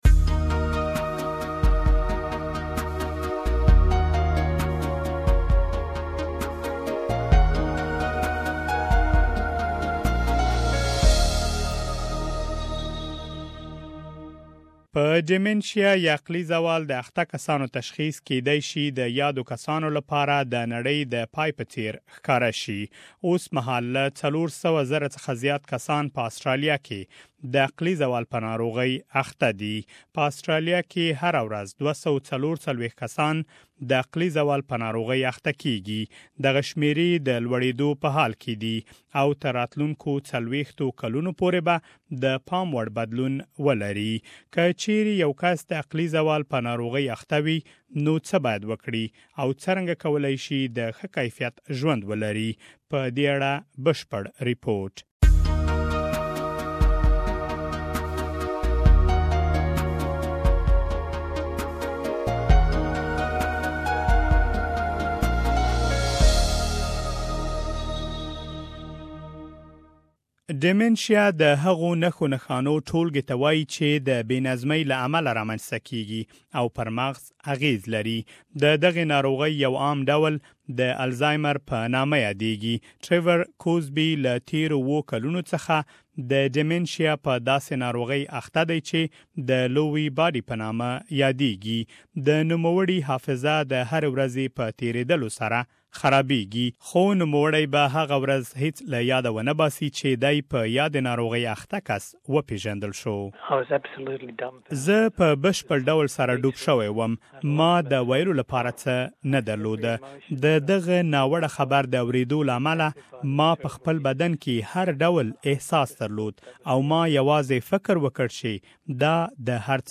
What should you do after a diagnosis and can you live a quality life with dementia? Please listen to the full report here.